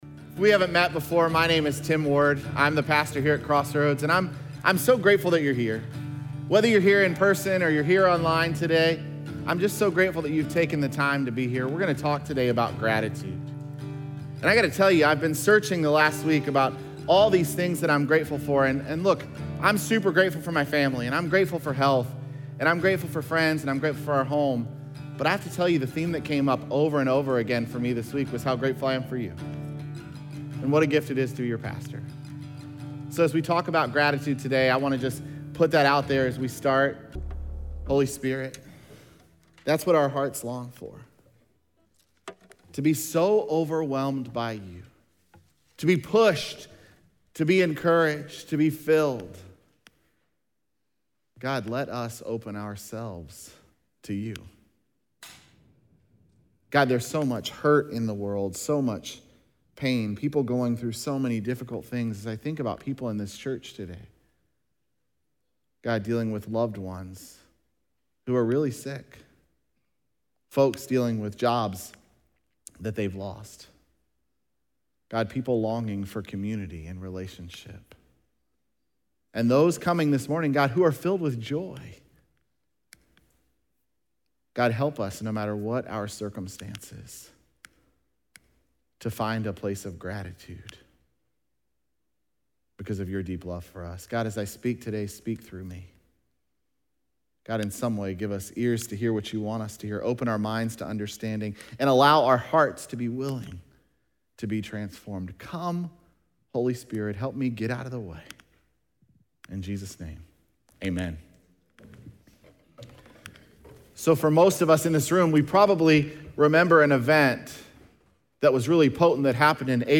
Sunday Morning Message Gratitude Adjustment